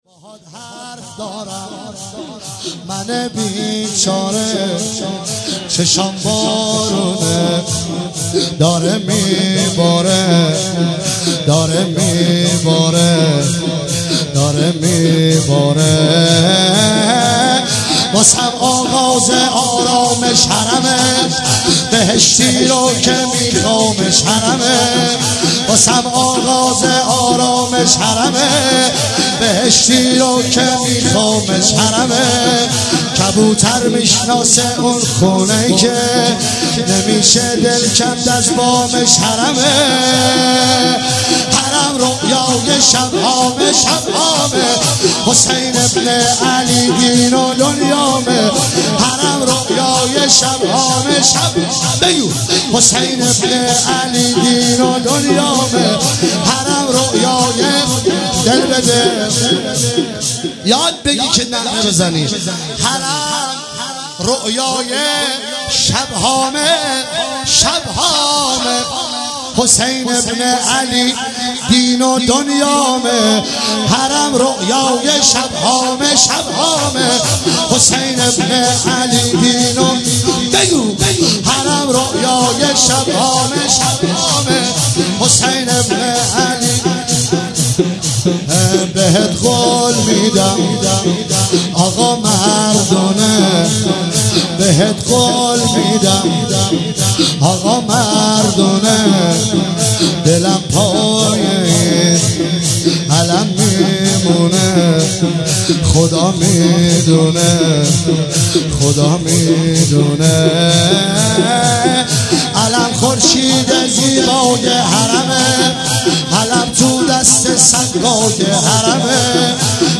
هیئت ریحانه الحسین (ع) – شهرستان دزفول
شور باهات حرف دارم منه بیچاره